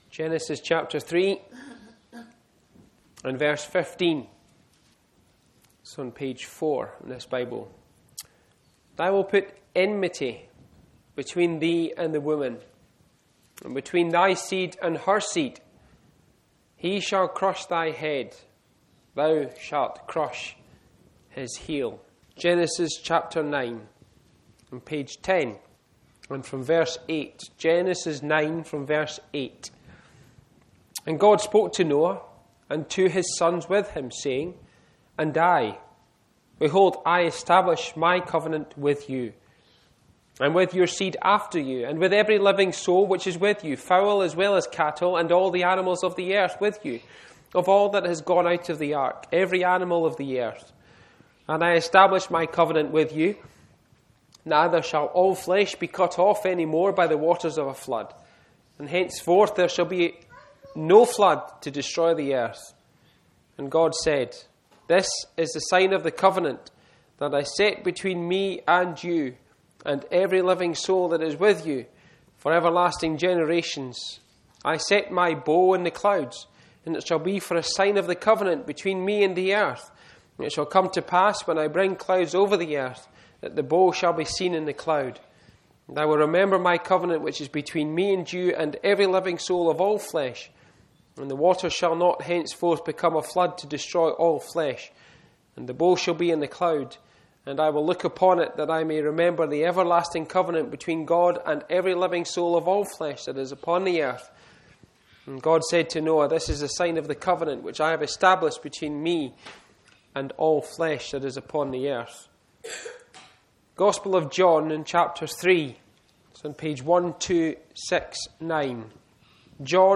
A Gospel message about God’s unchanging promises—from the first promise of redemption to the fulfilment in Christ—showing how every word of God finds its “Yes” and “Amen” in Him.